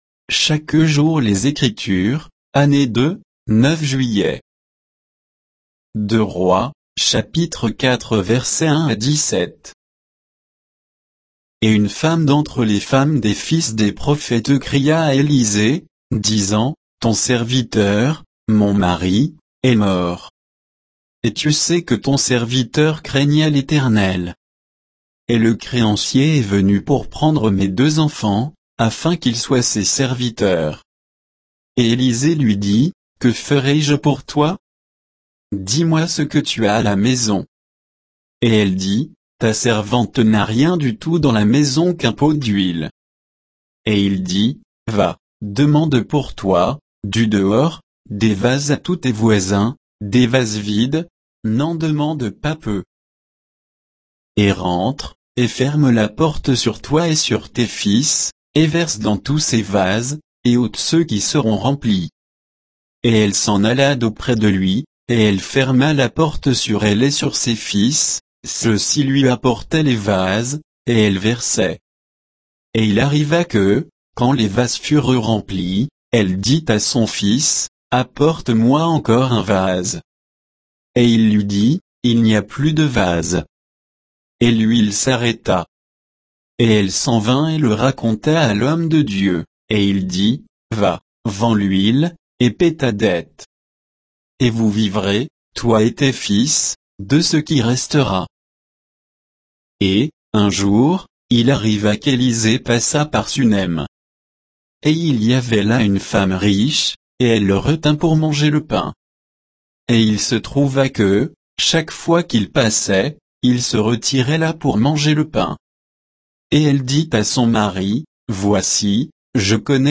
Méditation quoditienne de Chaque jour les Écritures sur 2 Rois 4, 1 à 17, par J.Koechlin